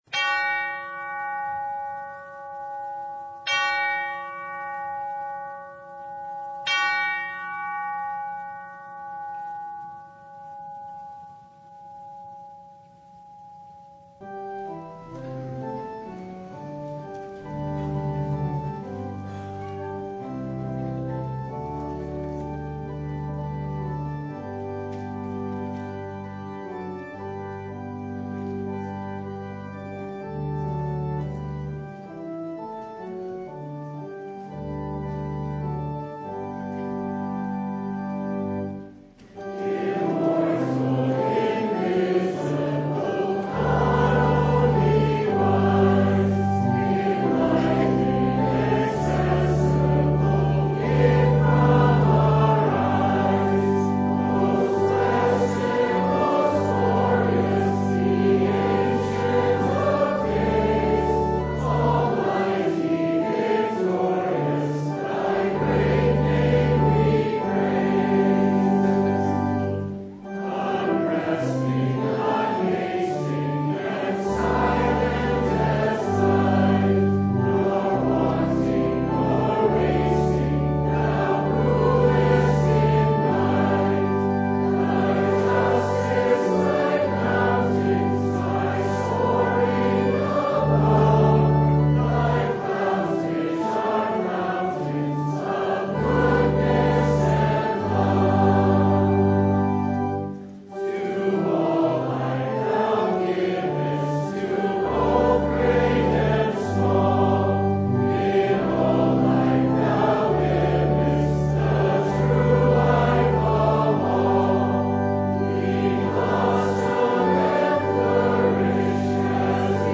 Service Type: Sunday
Download Files Bulletin Topics: Full Service « Who We Are: Children of God Resurrection or Not?